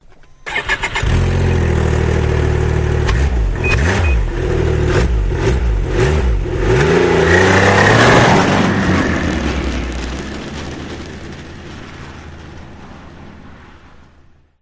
На этой странице собраны звуки снегохода: рев мотора, скрип снега под гусеницами, свист ветра на скорости.
Звук завели снегоход и умчались